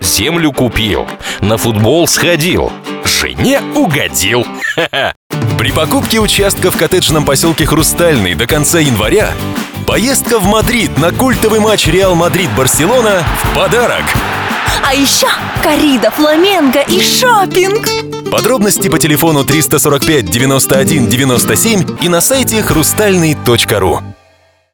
Радиоролик Категория: Копирайтинг